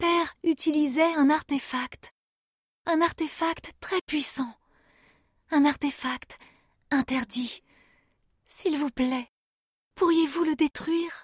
Jeune Fille (Jeu vidéo)